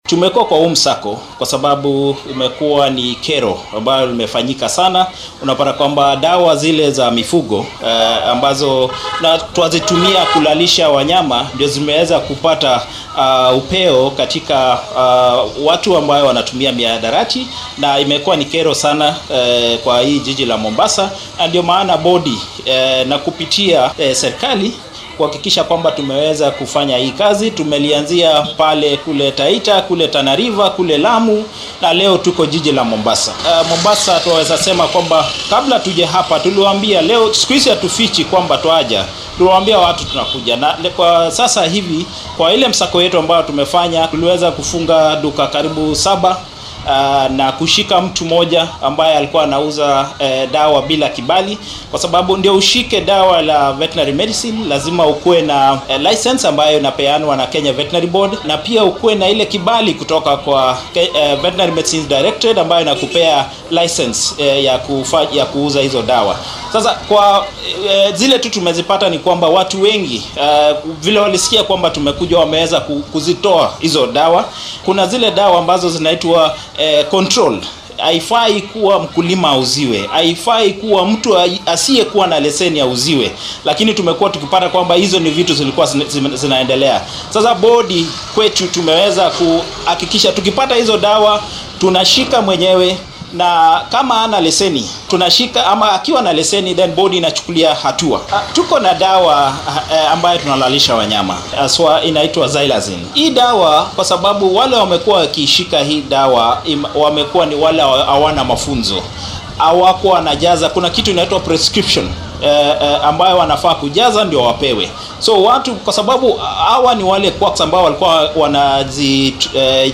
Waxaa howlgalkan warbaahinta faahfaahin ka siiyay mid ka mid saraakiisha guddiga caafimaadka xoolaha ee dalka.
Sarkaalka-caafimaadka-xoolaha-ee-Kenya.mp3